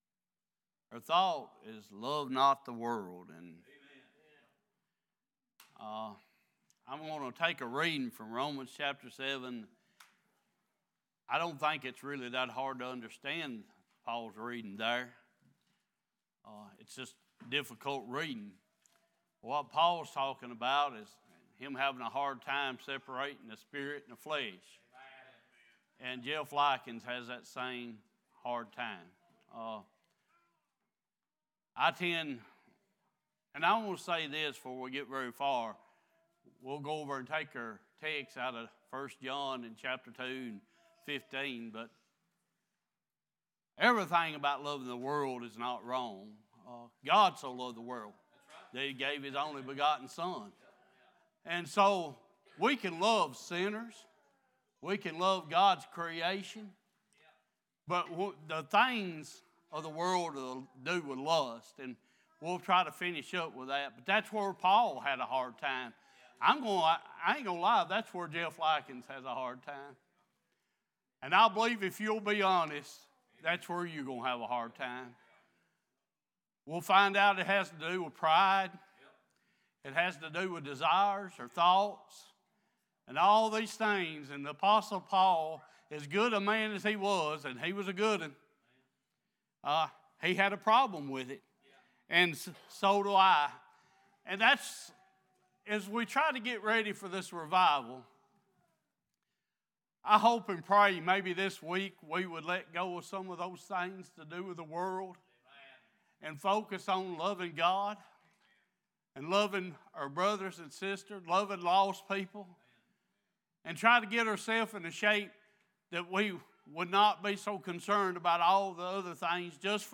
Sunday Morning Passage: Romans 7:15-25, 1 John 2:15-17, John 15:12-19 Service Type: Worship « Highway to Heaven What Do You Want Me To Do For You?